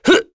Lusikiel-Vox_Attack1.wav